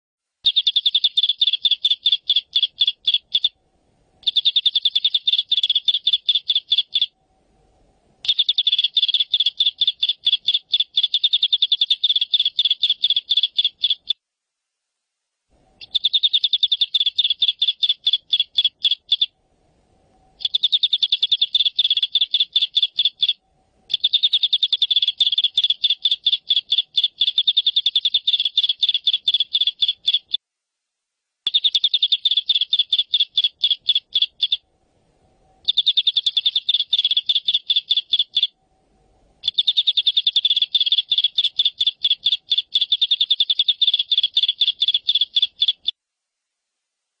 黄雀鸟鸣声